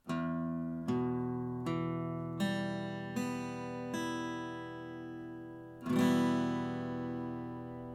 Der e-Moll-Akkord besteht aus den drei Tönen: E, G und H, die auch als Dreiklang bezeichnet werden.
e-Moll (Offen)
E-Moll-Akkord, Gitarre
E-Moll.mp3